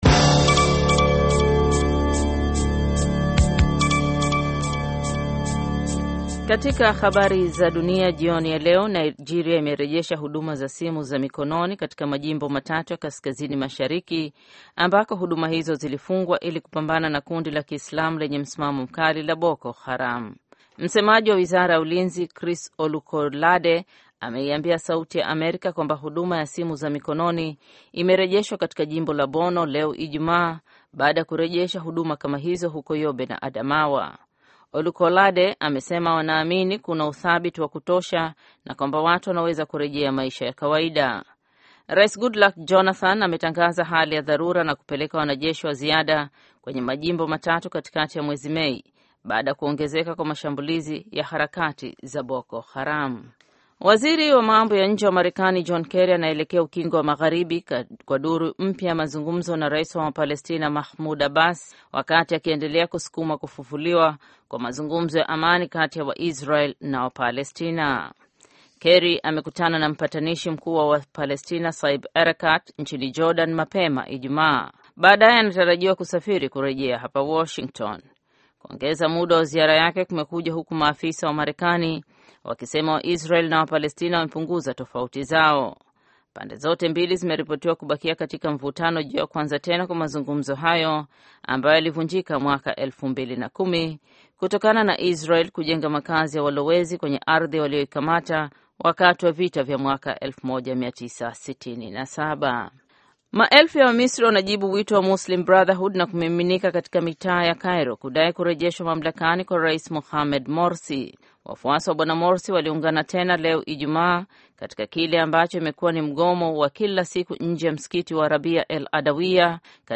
Taarifa ya Habari